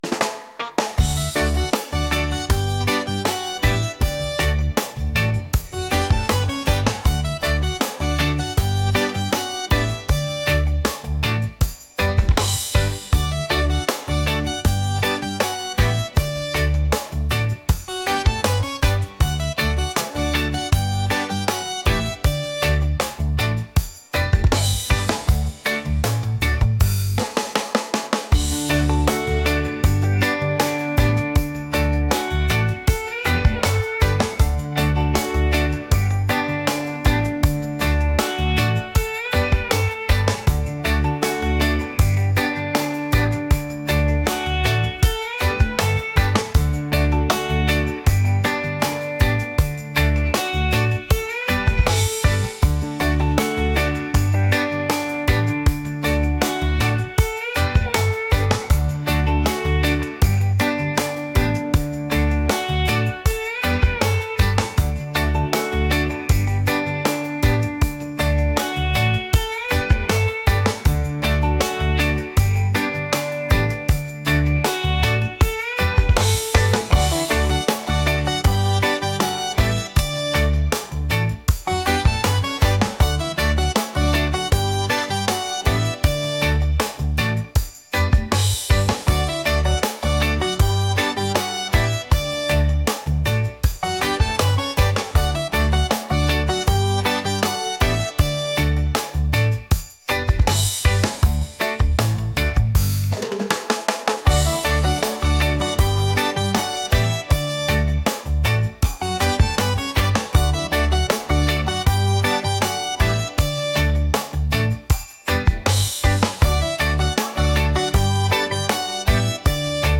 reggae | catchy | upbeat